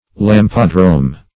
Search Result for " lampadrome" : The Collaborative International Dictionary of English v.0.48: Lampadrome \Lam"pa*drome\, n. [Gr.